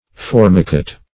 Formicate \For"mi*cate\ (f[^o]r"m[i^]*k[=a]t), v. i. [See
formicate.mp3